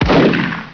Shot6
SHOT6.WAV